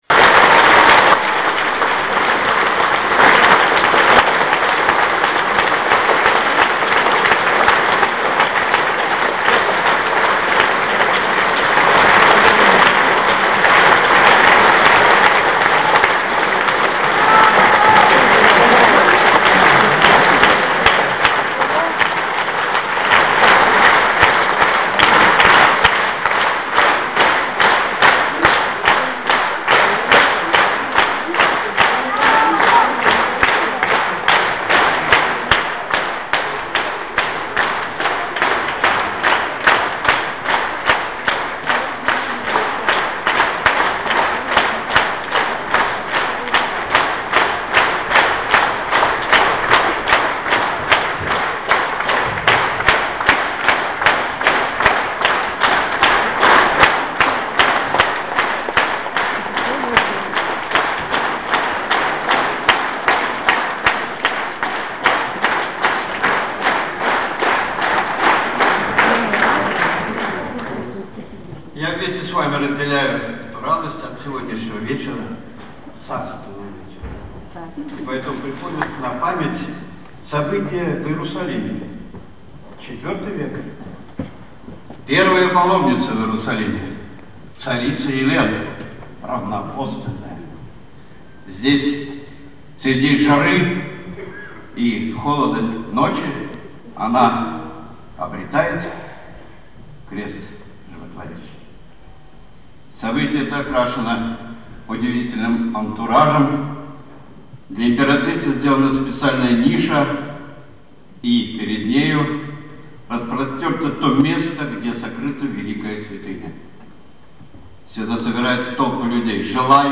На сцене областной филармонии состоялся концерт Народной артистки СССР Елены Васильевны Образцовой. Присутствующие на концерте прикоснулись к мировым музыкальным шедеврам и получили редкую возможность услышать арии из опер, романсы в исполнении певицы, талант которой признан во всех странах мира.